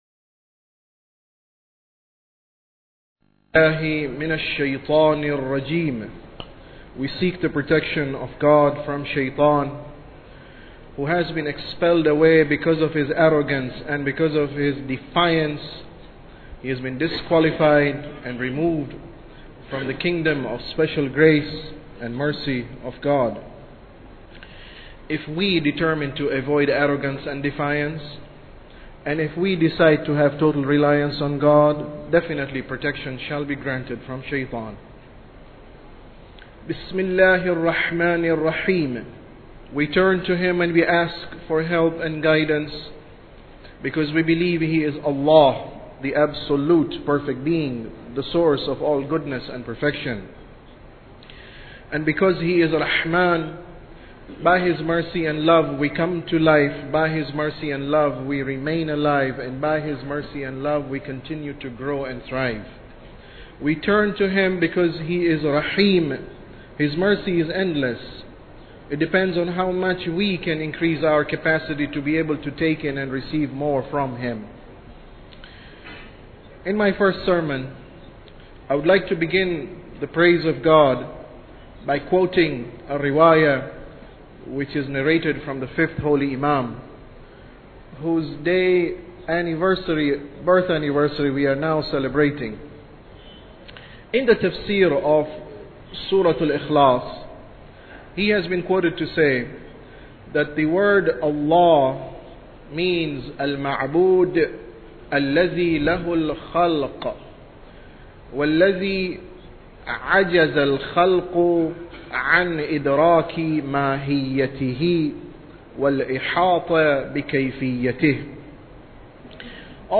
Sermon About Tawheed 8